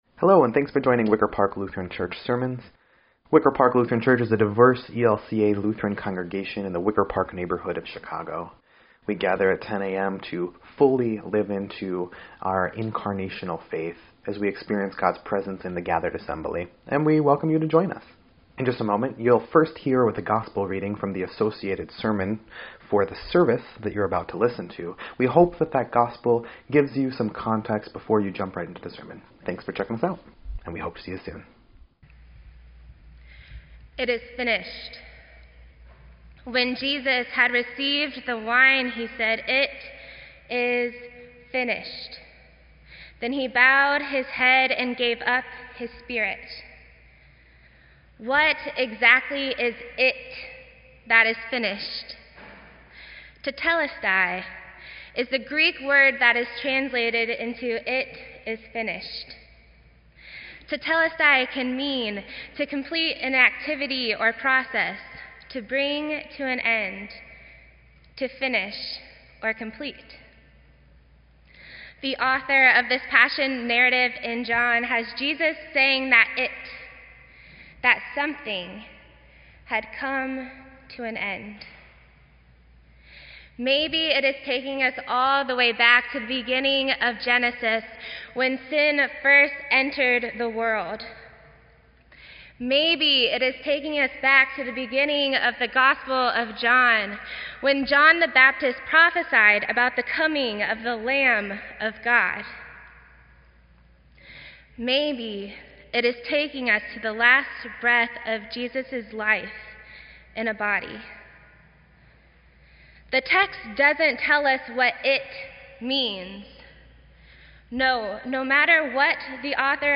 Good Friday
Sermon_3_30_18_EDIT.mp3